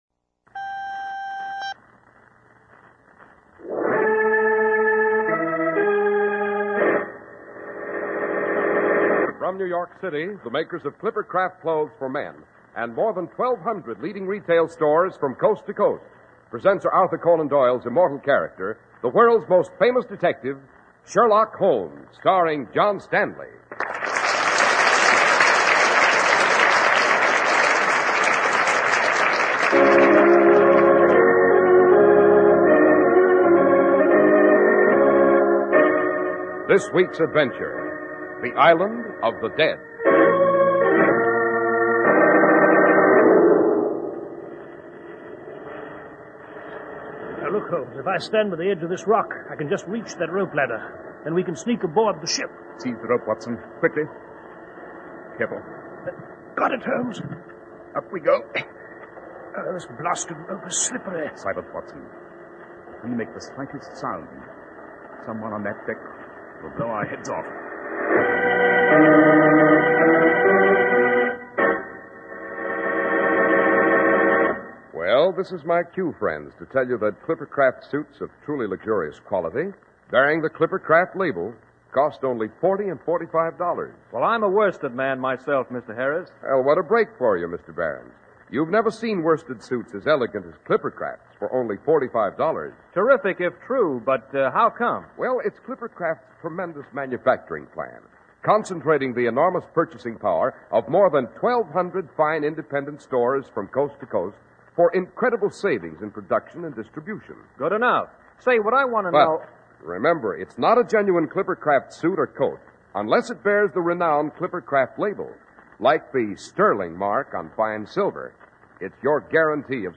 Radio Show Drama with Sherlock Holmes - The Island Of The Dead 1948